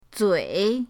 zui3.mp3